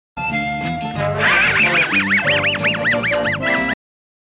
Muttley laugh